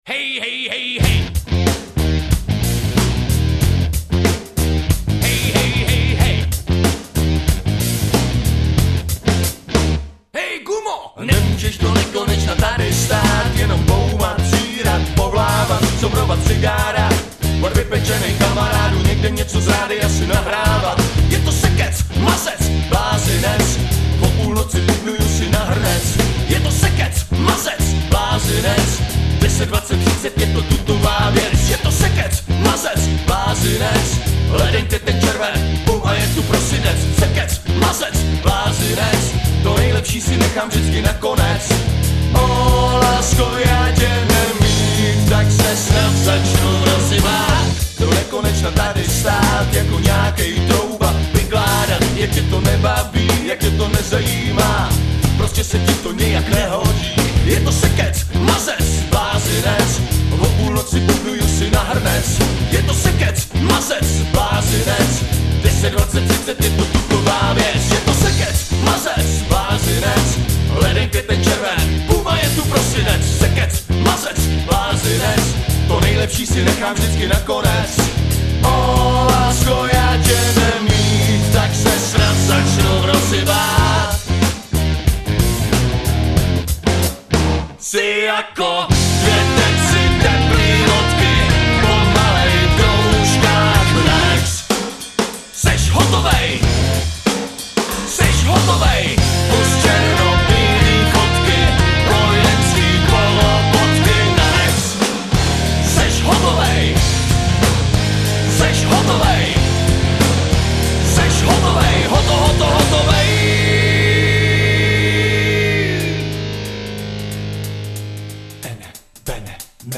klasického udergroundu z kytarového pera